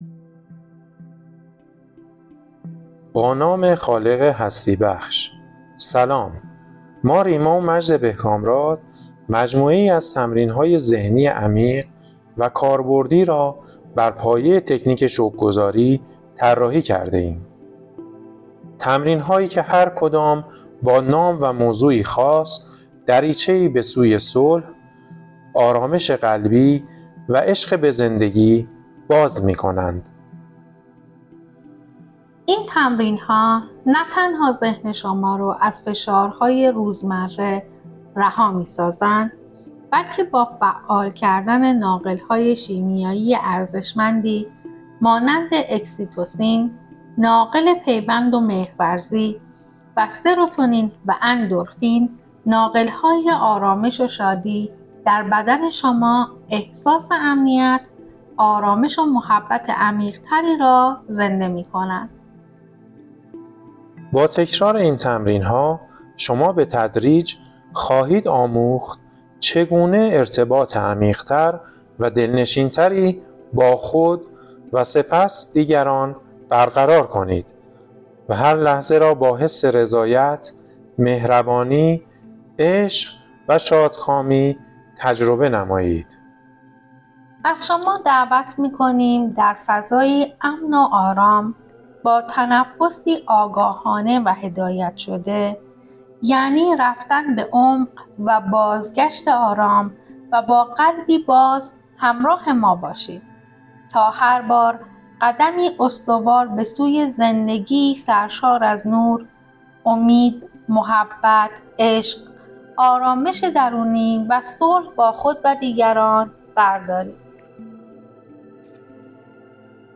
مدیتیشن شکرگزاری روز سوم | مدیتیشن اتصال به زمین